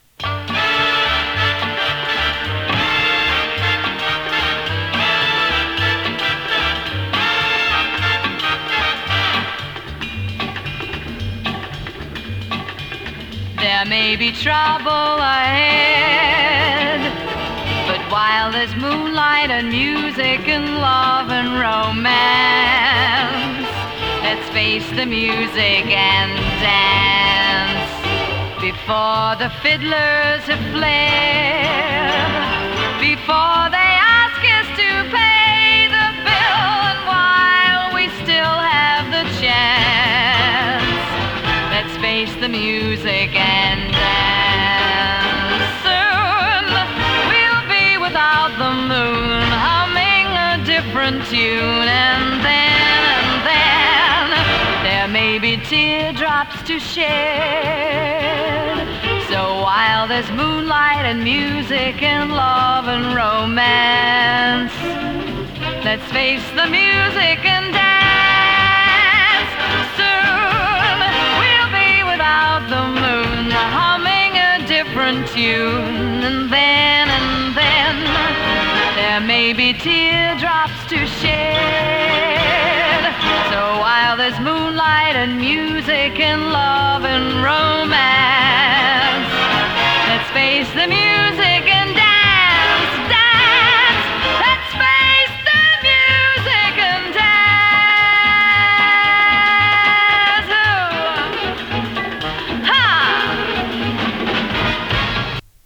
ラテン味
ビッグバンド ジャズボーカル